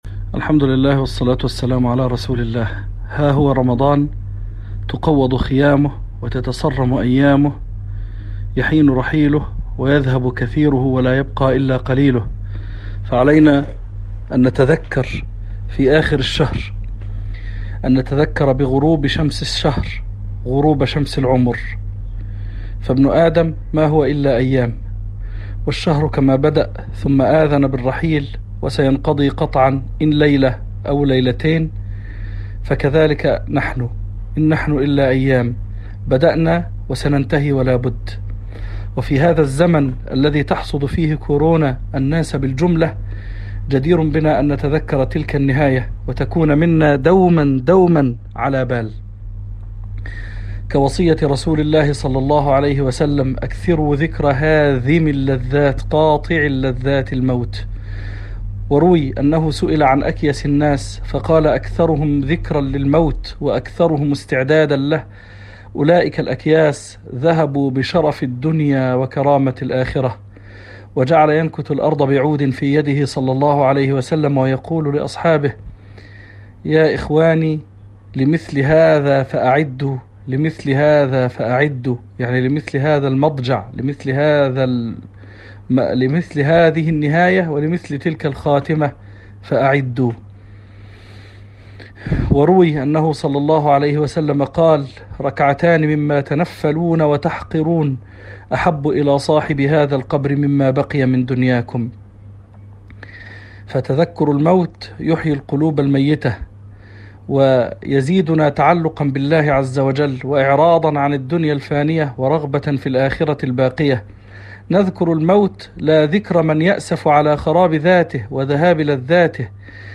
كلمة قصيرة عن الحث على الاجتهاد في نهاية رمضان وما نفعل بعدها.